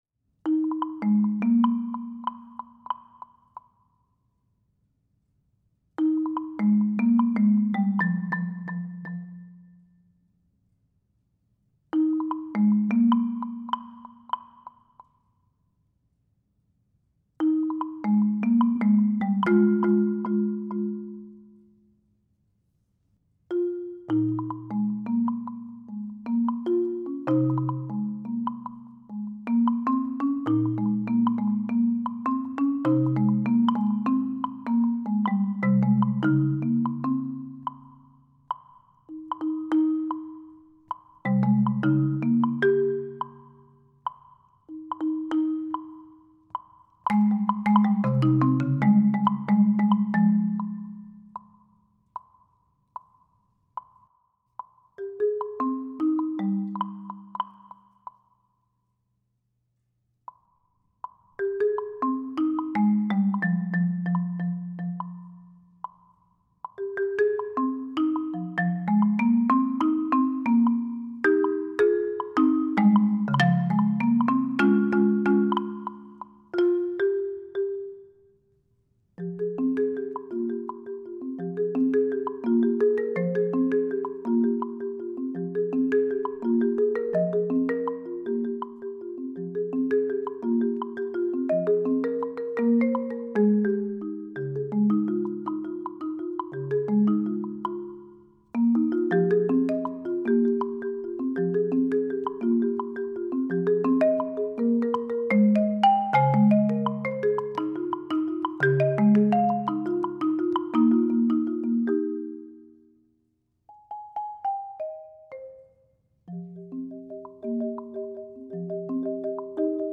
Voicing: Marimba and Woodblock